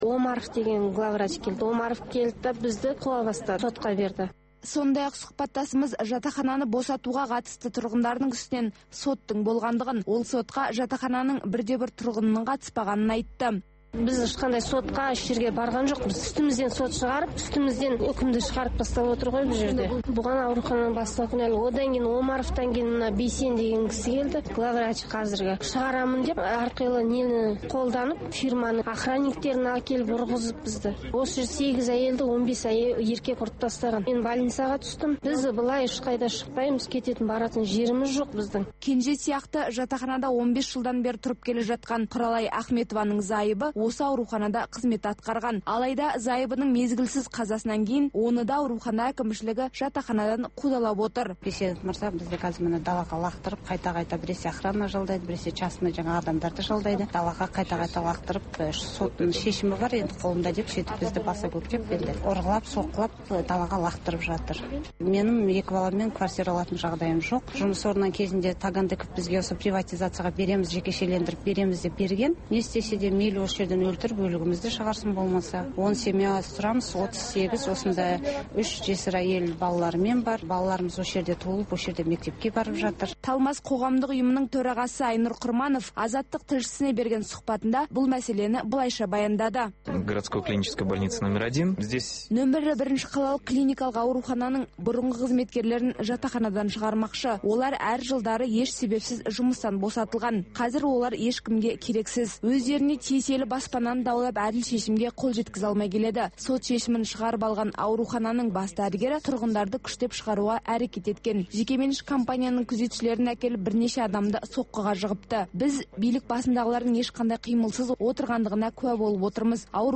Бүгінгі күннің өзекті тақырыбына талқылаулар, пікірталас, оқиға ортасынан репортаж, сарапшылар талдауы мен қарапайым азаматтардың еркін пікірі, баспасөз шолуы, тыңдарман ойы.